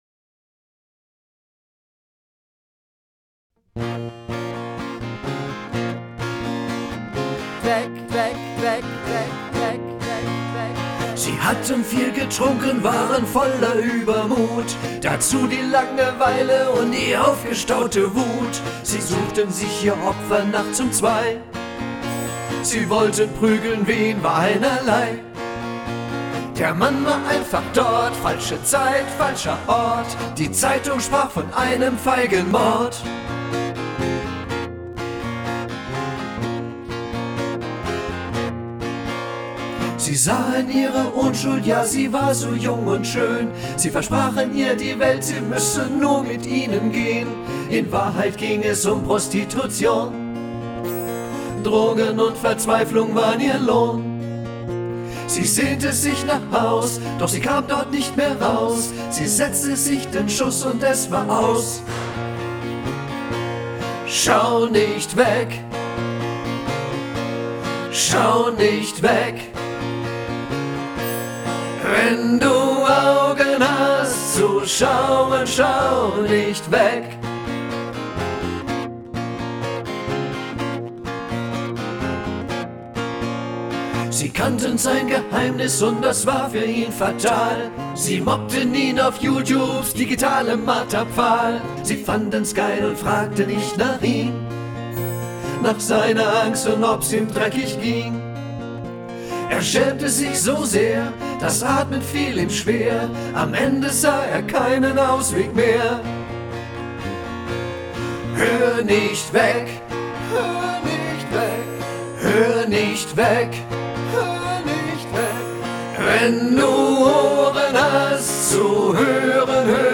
akustik-Version